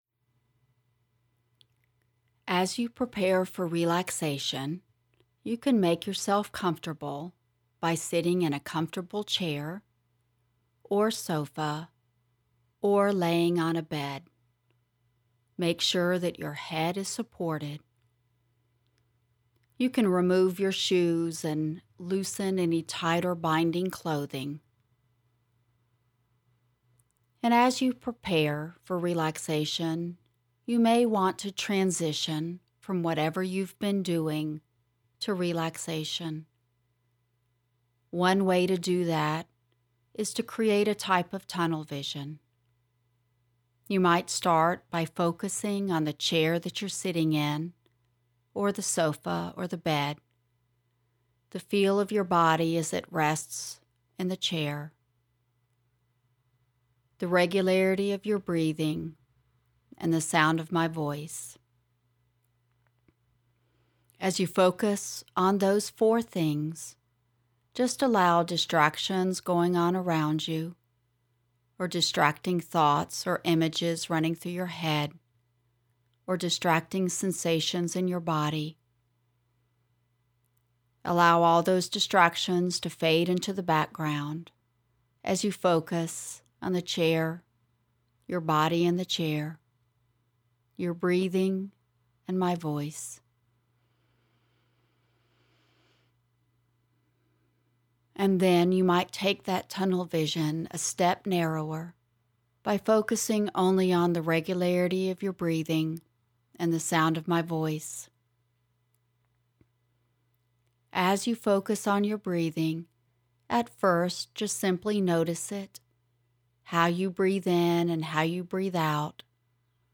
Muscle Relaxation
Muscle-Scan-Relax.mp3